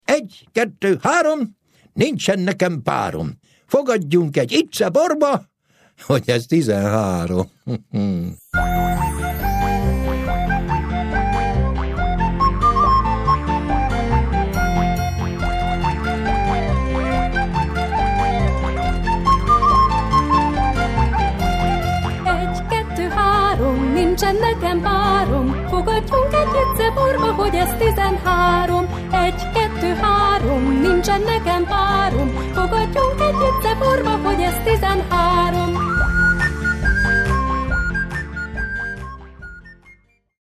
A mondókákat kisiskolások adják elő.